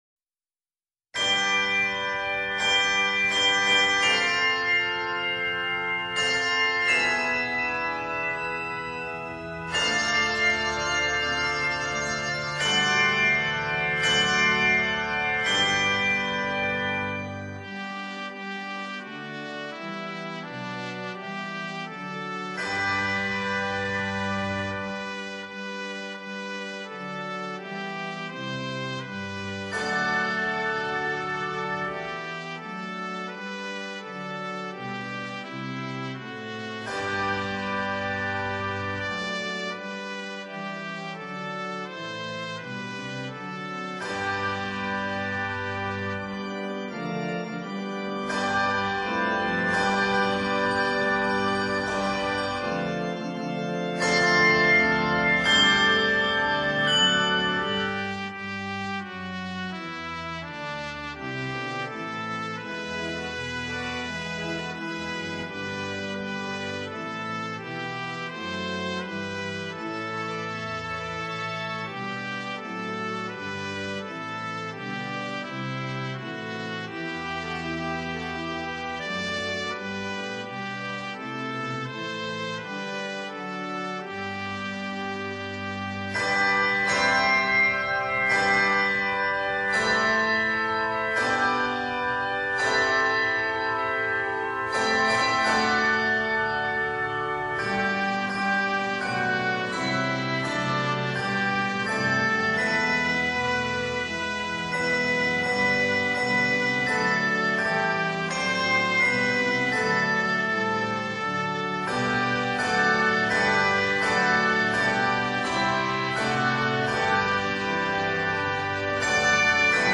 A majestic setting
Arranged in G Major, this work is 52 measures.